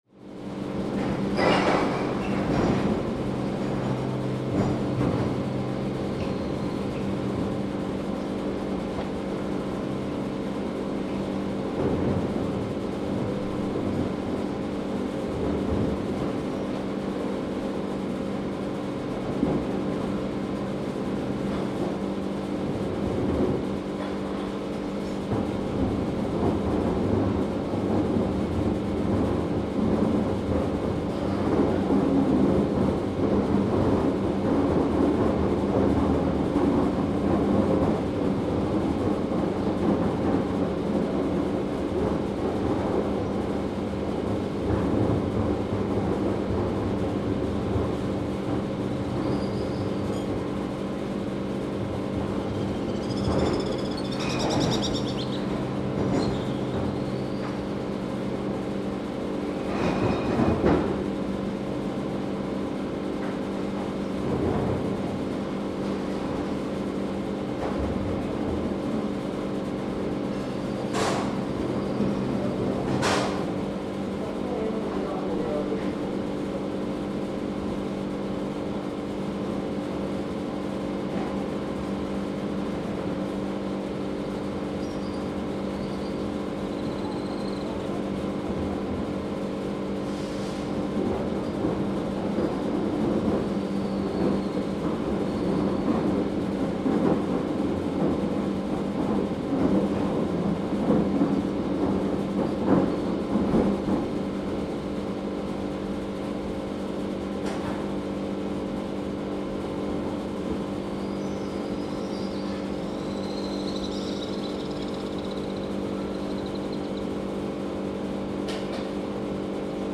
It's early June, 8 am.
It's Sunday, and the bells from the various towers across town are spilling their gongs.
Savagely squeaking swallows and the morning congregations of seagulls sound like a pastorale compared to the town’s growling bowels. Air conditioning units, pumps, kitchen ventilation, garbage trucks, delivery vehicles, wheels, and trolleys of all sorts.
The large ventilation unit drones are textural and multiphonic. Anarchy in the town’s electrical grid is constantly buzzing from nooks and crevices like some robo-brute. Sounds of the wagons for garbage collection, and the rivers of inbound and outbound suitcases are bouncing over the stone streets like a constant percussive development.